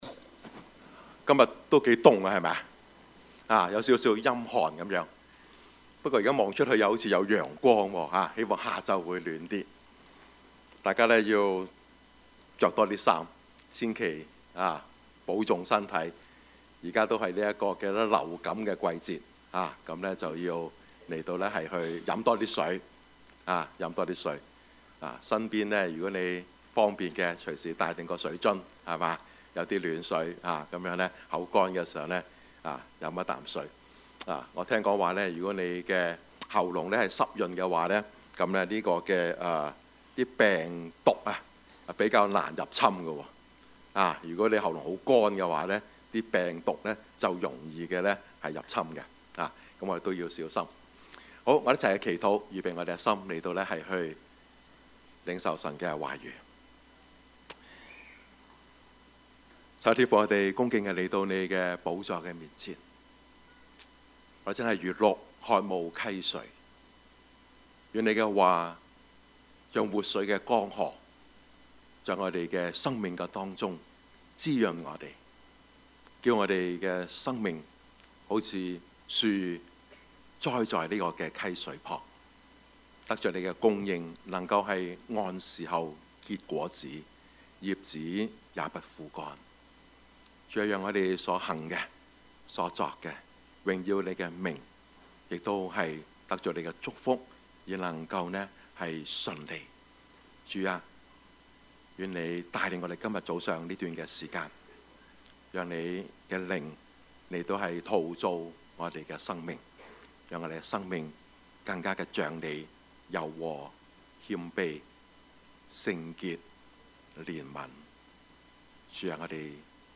Service Type: 主日崇拜
Topics: 主日證道 « 回到神的心意 流言蜚語，人身攻擊 »